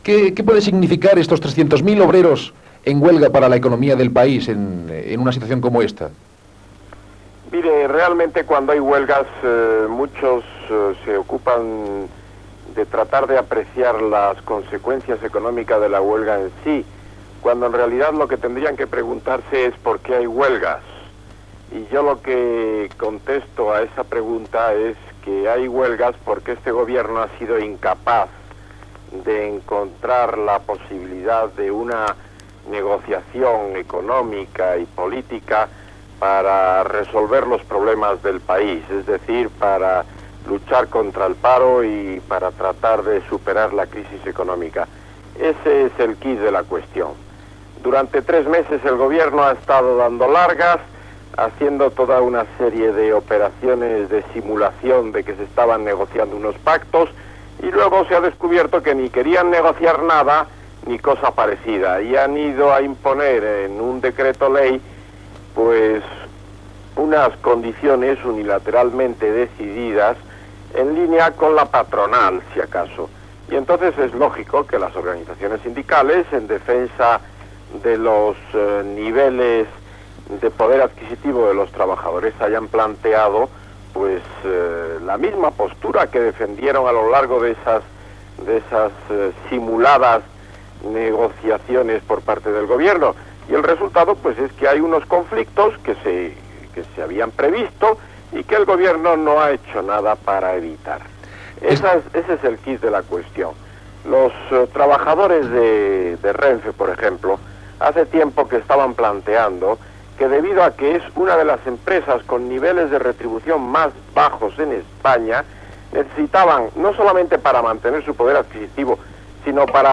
Entrevista al diputat Ramón Tamames sobre la vaga de treballadors
Entreteniment